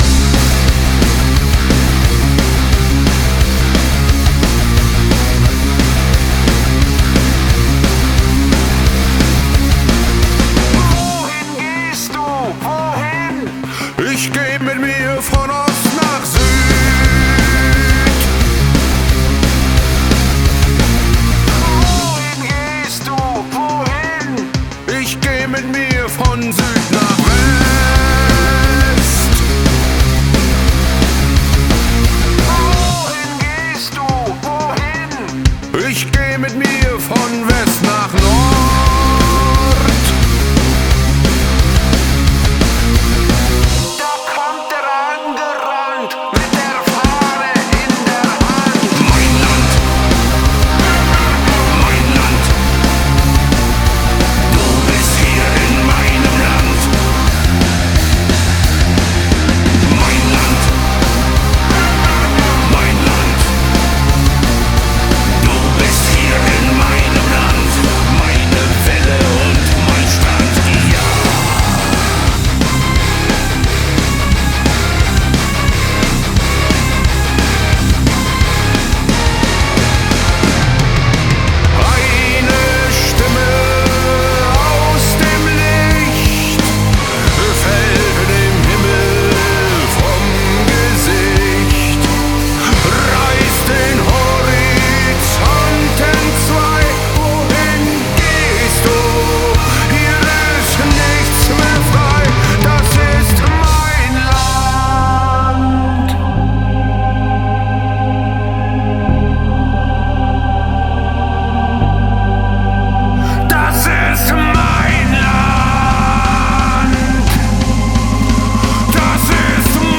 BPM88-176
Audio QualityCut From Video